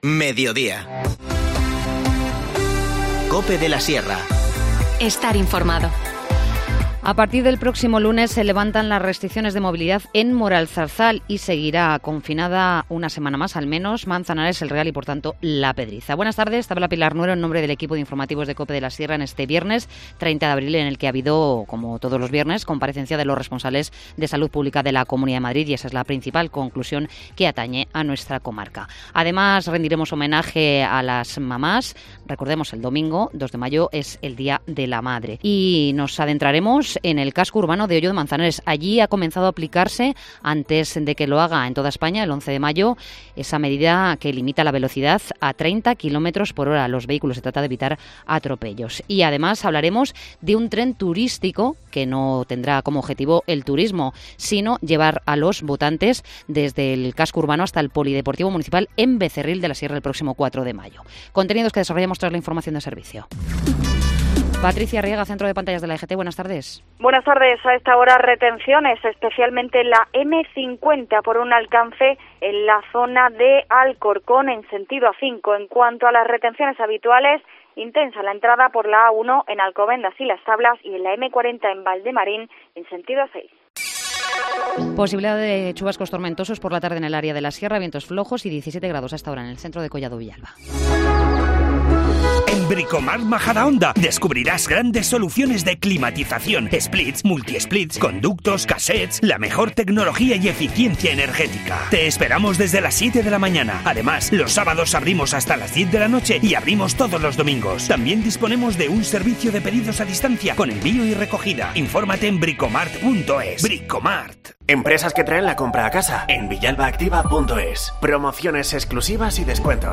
Informativo Mediodía 30 abril